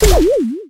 sprout_dryfire_01.ogg